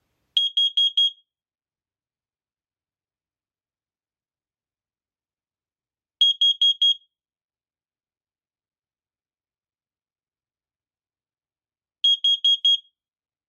• X-Sense CO03D Alarm:
Werden die entsprechenden Werte und Zeitdauern erreicht, alarmiert der CO-Melder durch einen 4-maligen Alarmton im Abstand von ca. 6 Sekunden und die rote Alarm LED blinkt.
x-sense-co03d-co-melder-alarm.mp3